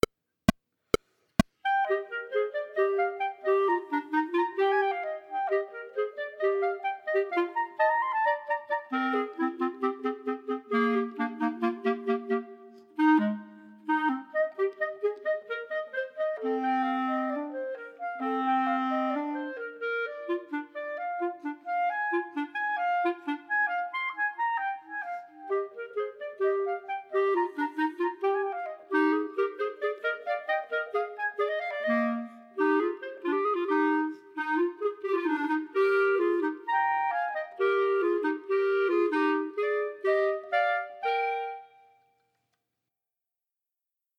There is a lot of dynamic drama here, so make the contrasts obvious (soft vs. loud, smooth vs. accented, staccato vs. slurred) but tastefully done (good tone and intonation). I chose 132 as a tempo, wanting it bright as I could perform cleanly.
12-Haydn, Allegro, Chamber Music for 3 Clarinets, Vol. II, minus 3rd part